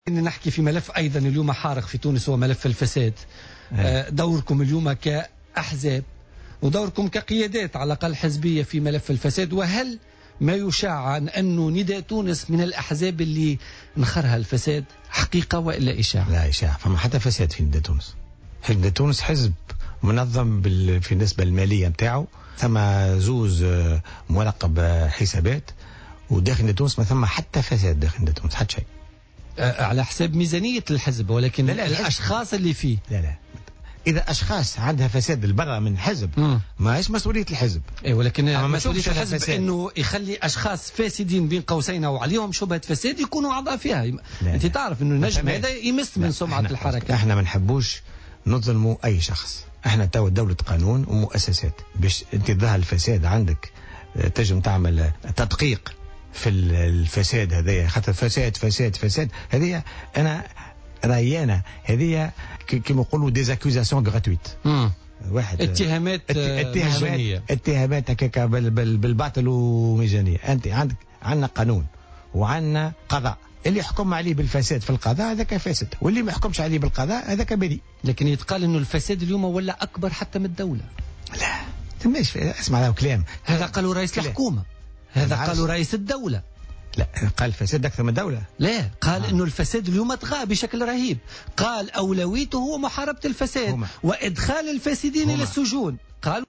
وأضاف في مداخلة له اليوم في برنامج "بوليتيكا" أن كل الاتهامات الموجهة للحزب اتهامات مجانية، مؤكدا أن نداء تونس حزب منظم و يخضع لعمليات مراقبة و تدقيق في مجال التصرف في ميزانيته.